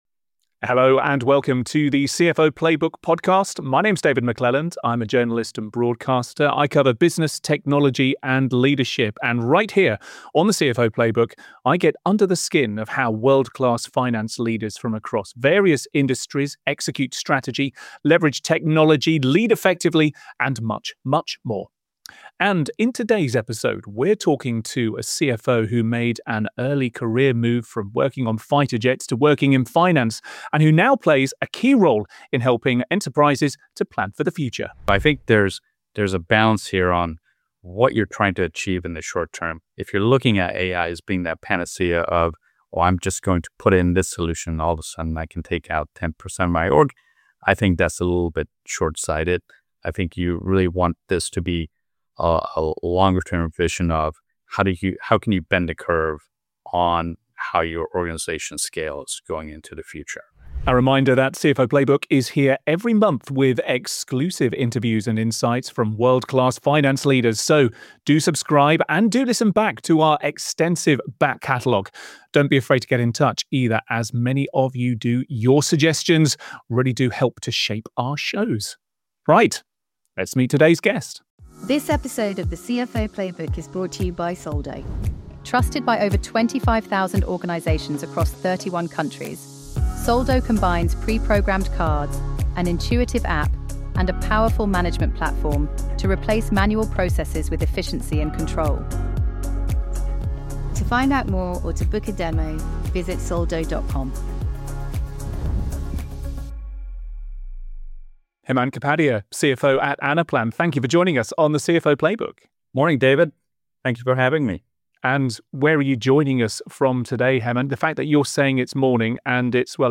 The CFO Playbook features interviews with world-class CFOs, finance leaders and founders from some of the fastest growing companies.